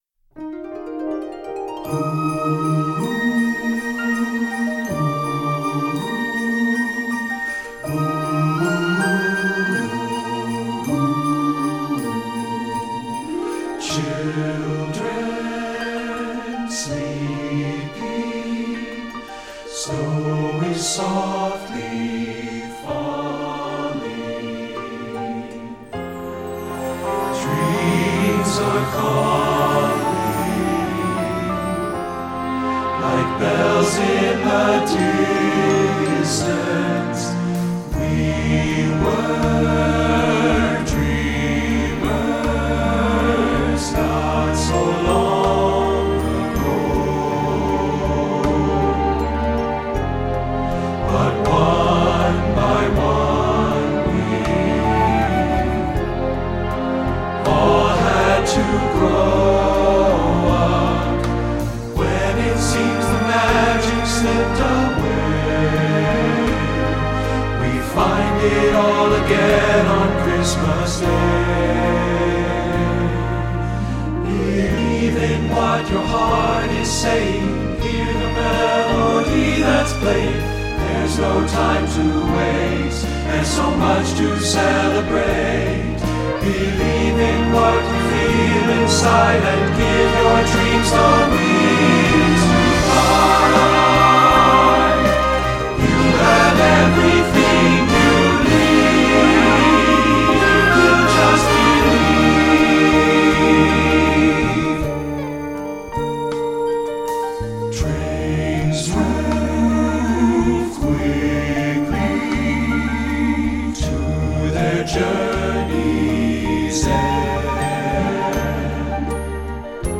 Voicing: TTBB and Piano